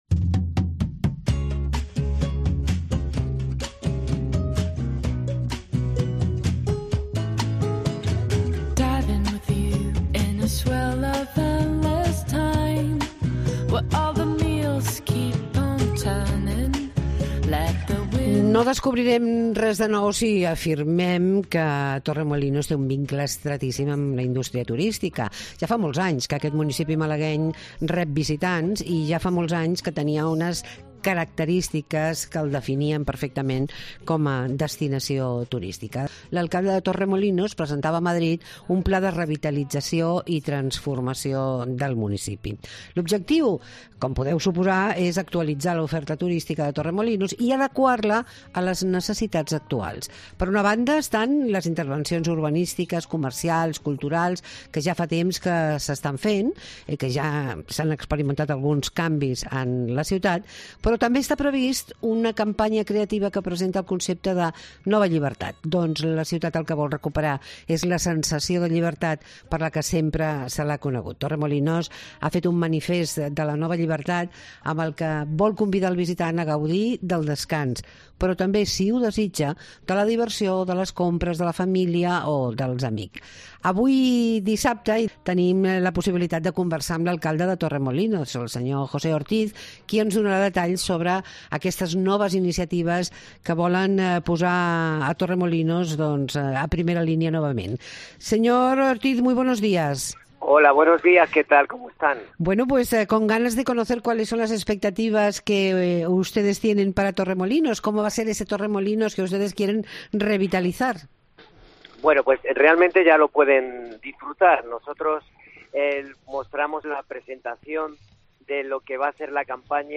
Torremolinos se reinventa como destino de vacaciones. Hablamos con el alcalde, José Ortíz